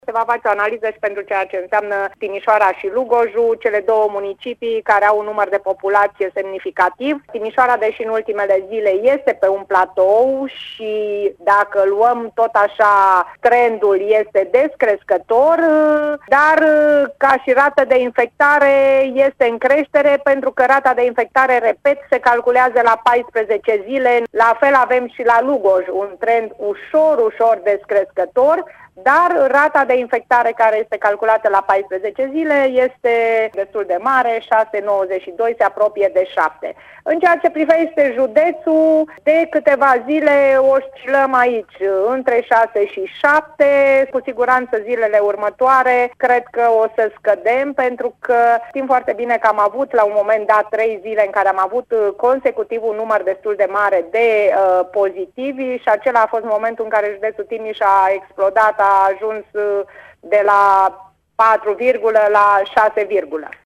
Prefectul de Timiș, Liliana Oneț, a precizat, la Radio Timișoara, că numărul îmbolnăvirilor s-a menținut constant, ușor descrescător, în ultimele zile, dar indicele la 14 zile este ridicat astfel că se va trimite o informare către Institutul Național de Sănătate Publică.